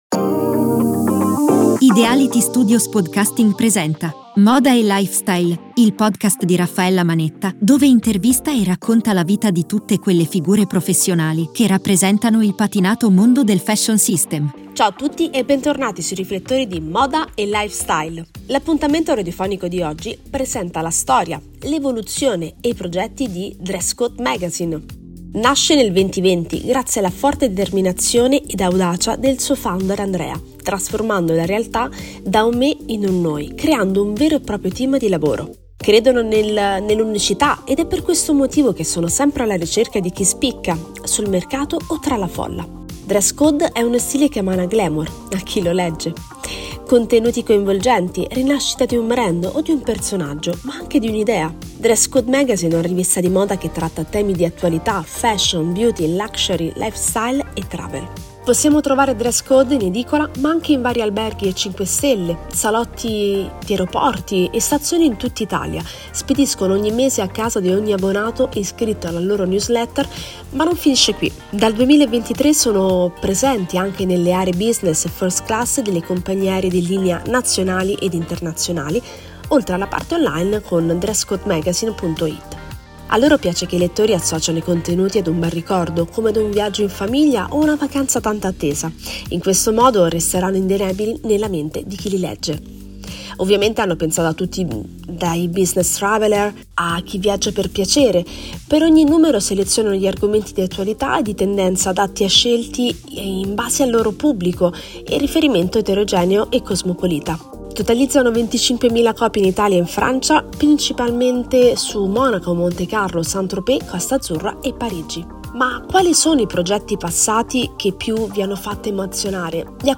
Interviste Radiofoniche
Il nostro appuntamento di oggi si conclude qui, grazie per averci seguito dalla vostra radio preferita.